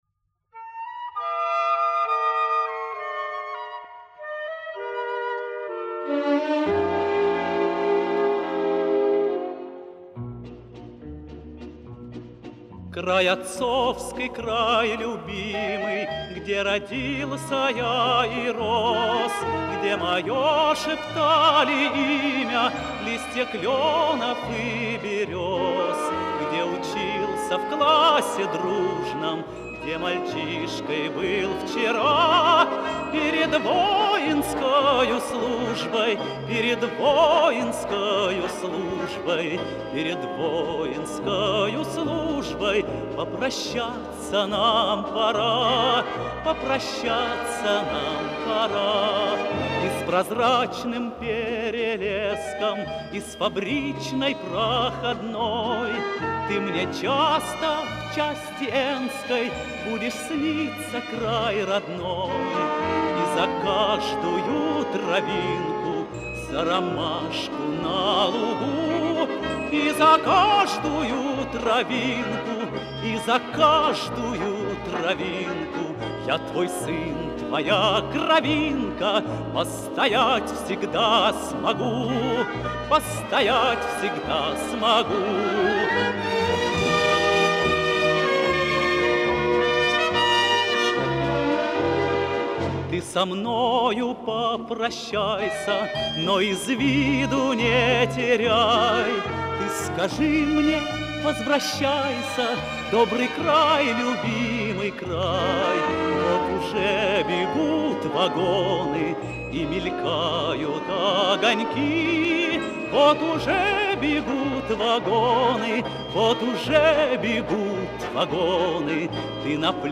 Песня о Родине 1970-х